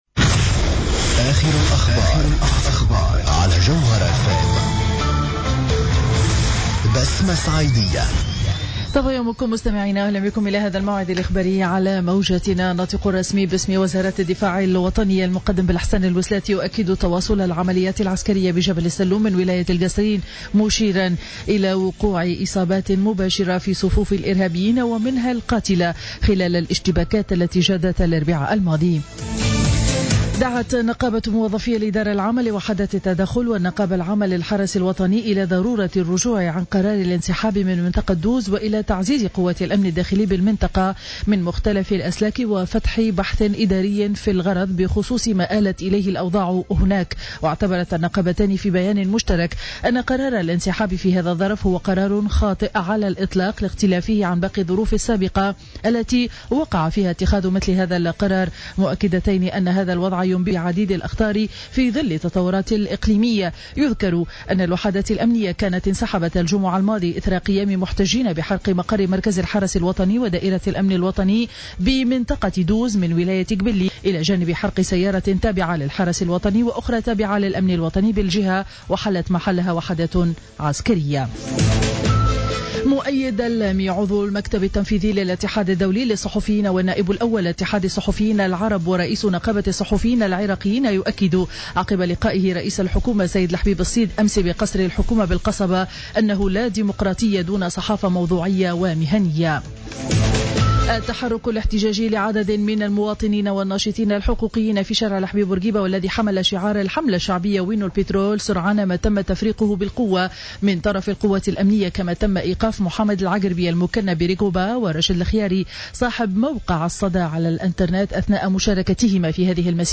نشرة أخبار السابعة صباحا ليوم الأحد 07 جوان 2015